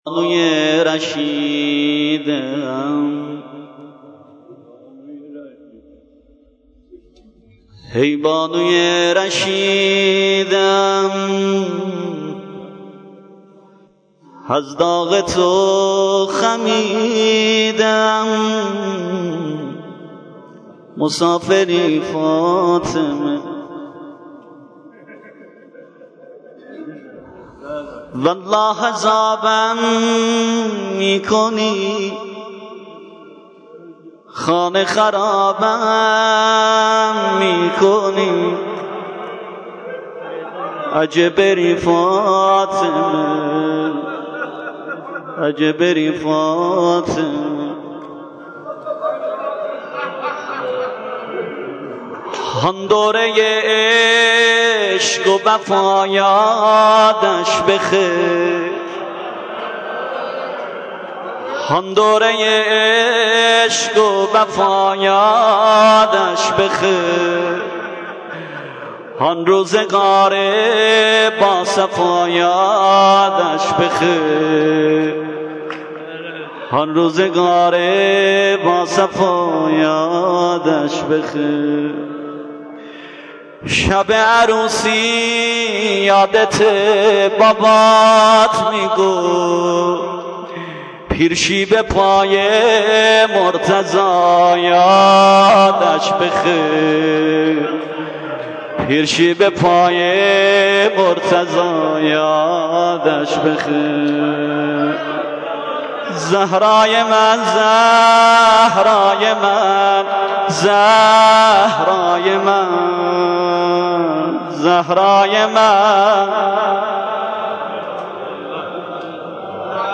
مداحی و نوحه